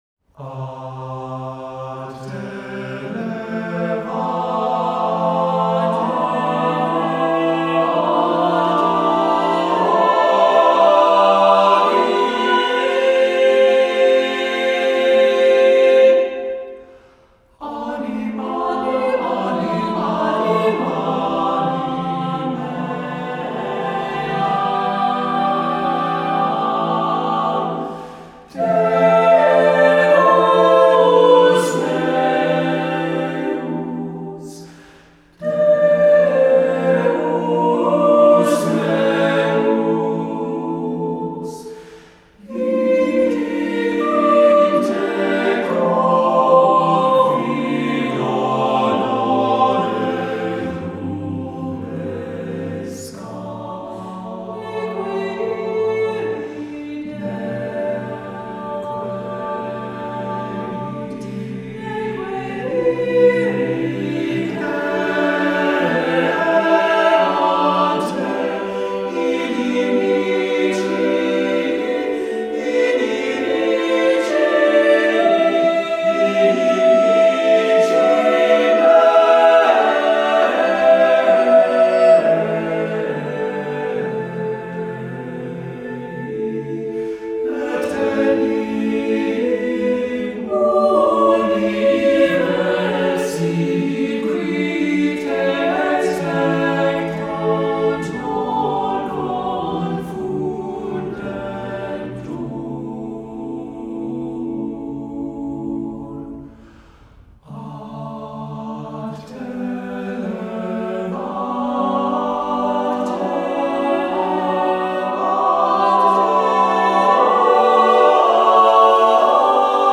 Voicing: SATB, a cappella